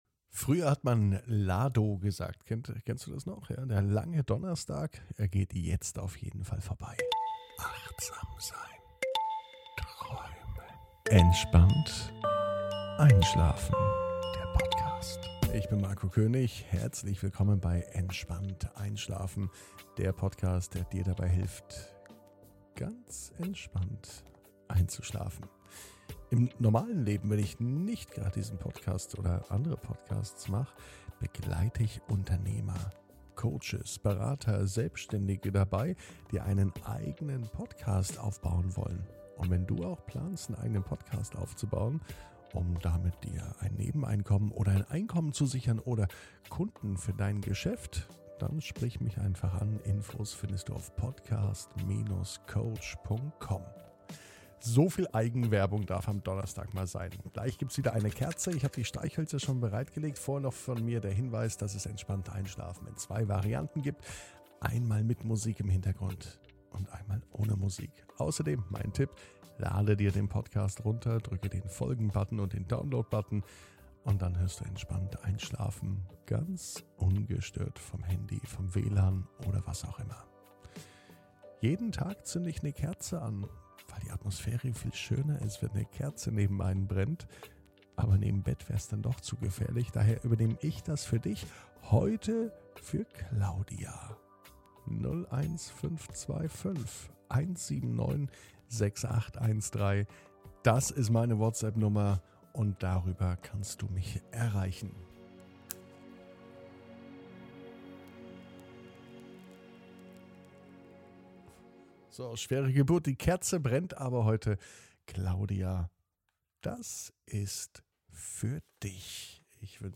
(ohne Musik) Entspannt einschlafen am Donnerstag, 24.06.21 ~ Entspannt einschlafen - Meditation & Achtsamkeit für die Nacht Podcast